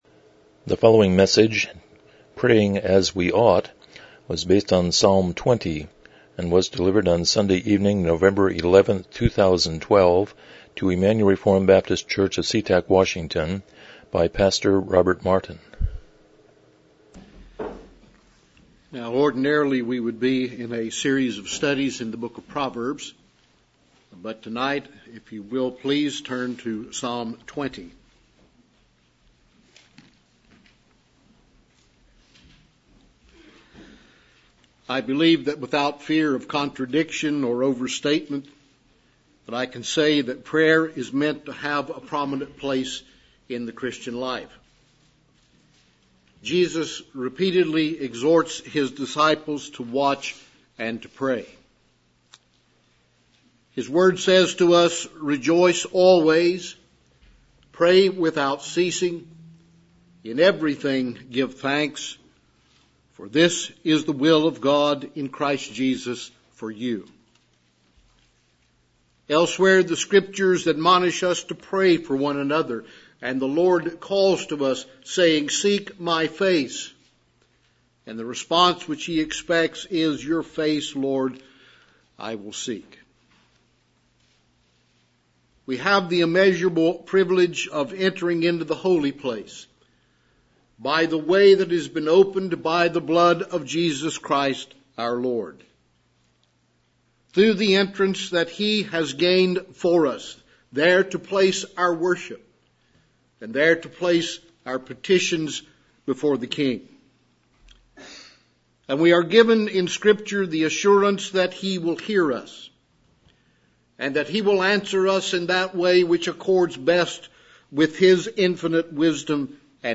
Psalm 20:1-9 Service Type: Evening Worship « 17 The Sermon on the Mount